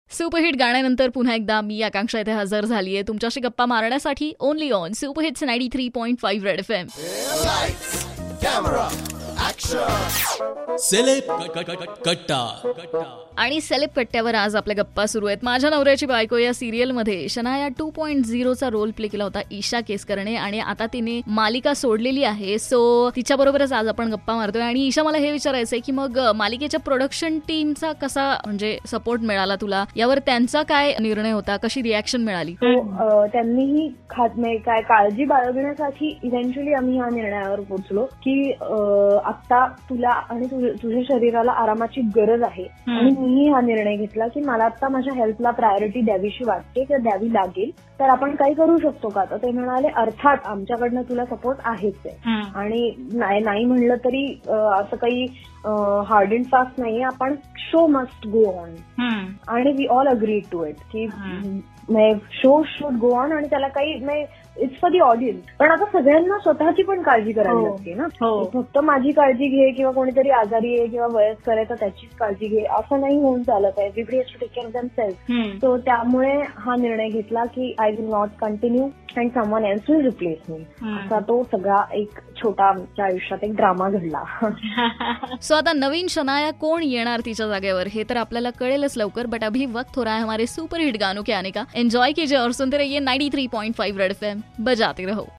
took an interview of famous actress